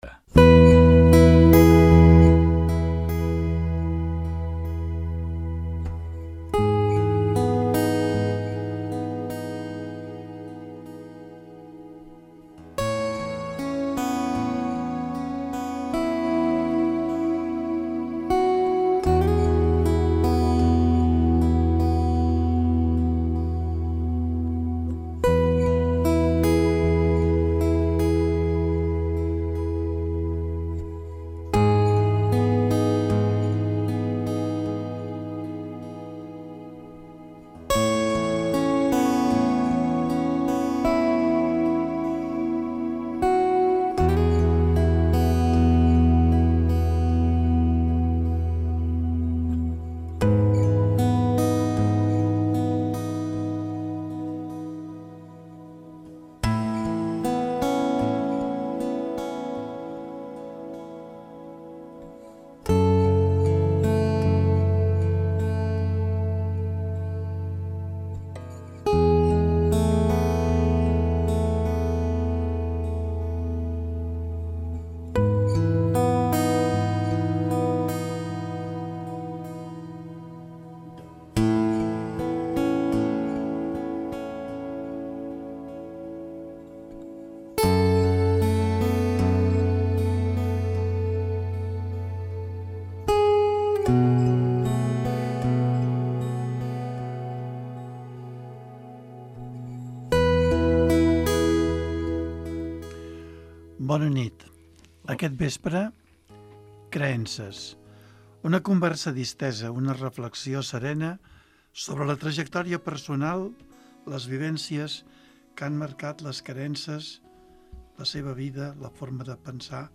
És un dels programes estrella de l'emissora amb més de 20 temporades en antena i amb més de 600 entrevistes a l'esquena parlant amb castellarencs i castellarenques que mostren el seu estil.